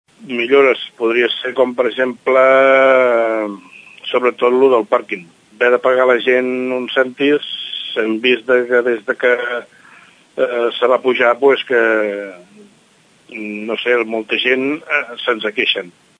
L’Info Mercat de Ràdio Tordera parla amb els marxants del mercat dels diumenges.